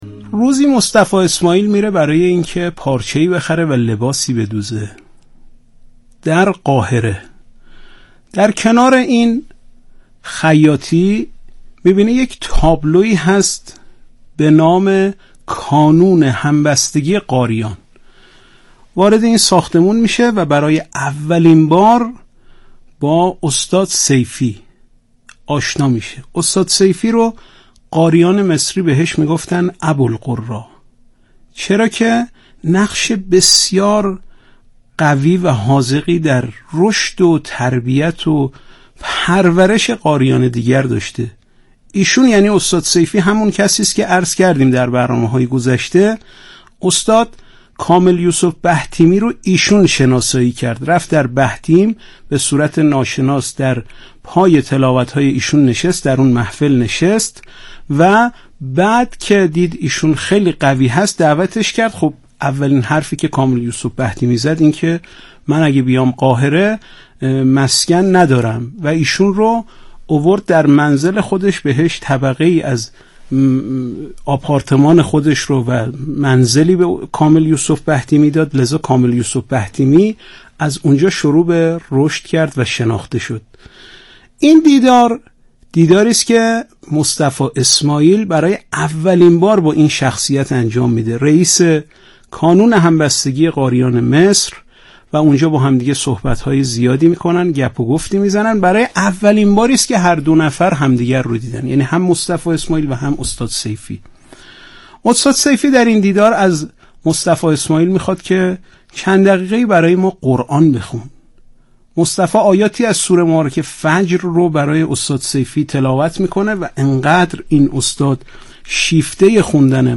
یادآوری می‌شود، این تحلیل در برنامه «اکسیر» از شبکه رادیویی قرآن پخش شد.